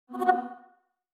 SMS Alert
Original Essential PH-1 notification sounds.